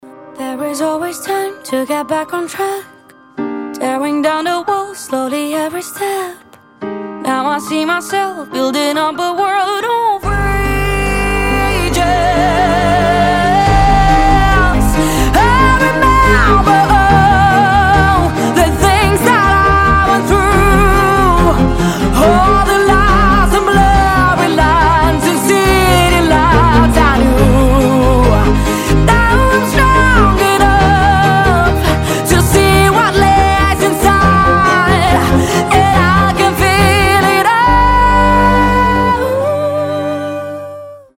• Качество: 320, Stereo
скрипка
пианино
виолончель
оркестр
красивый вокал
сильный голос